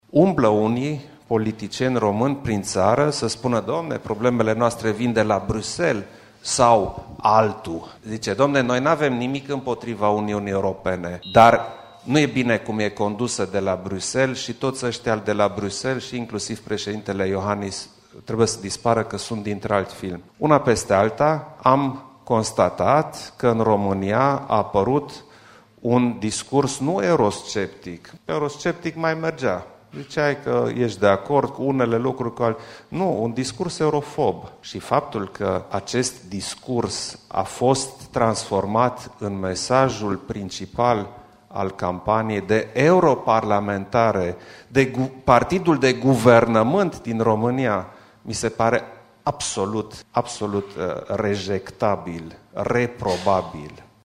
Președintele Klaus Iohannis și-a lansat la Timișoara cea mai nouă carte
Președintele a explicat cu ocazia lansării cum a decis să scrie o astfel de carte și ce conține ea.